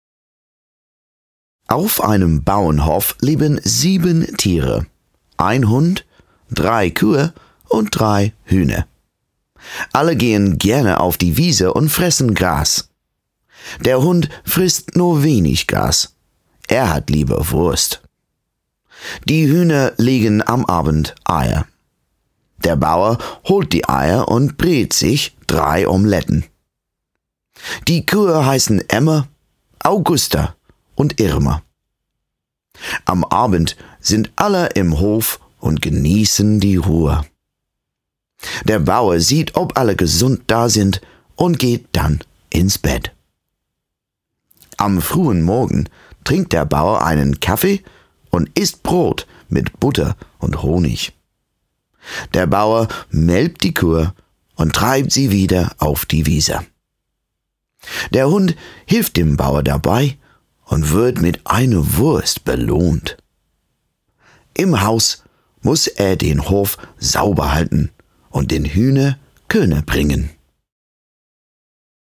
Young Adult, Adult
Has Own Studio